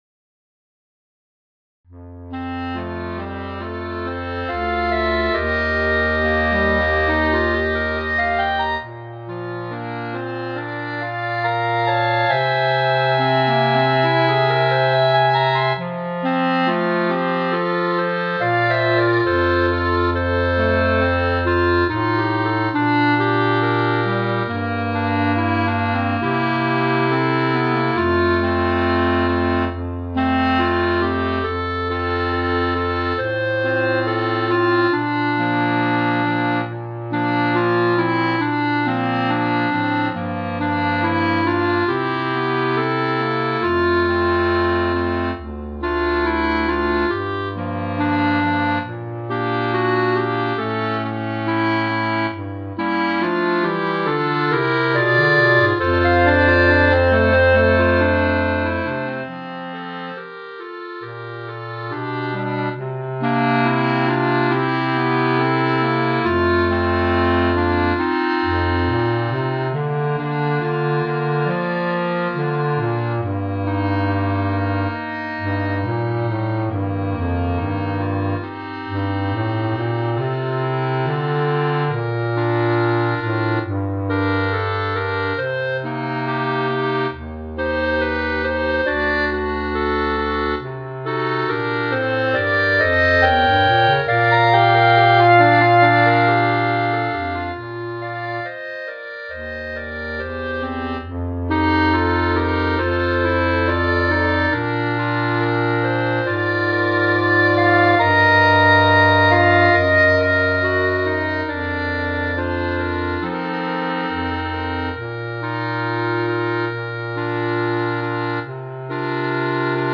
B♭ Clarinet 1 B♭ Clarinet 2 B♭ Clarinet 3 Bass Clarinet
单簧管四重奏
流行